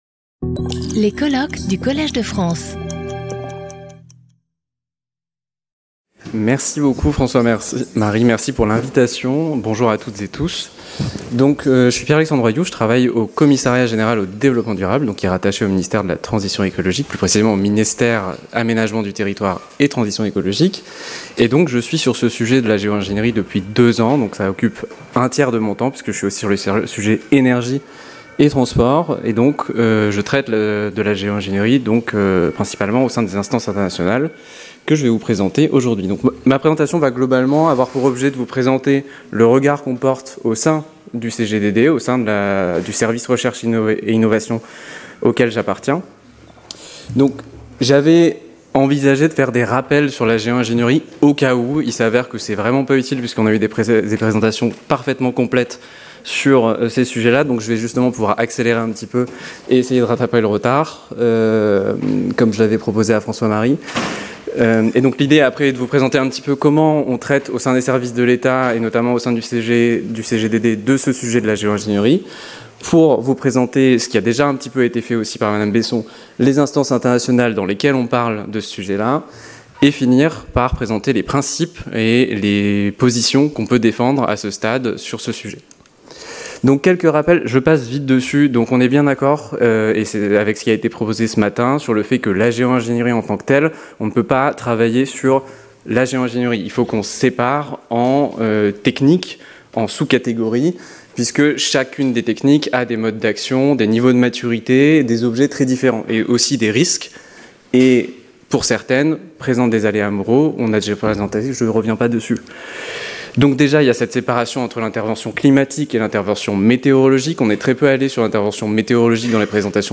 Geoengineering from a political perspective | Collège de France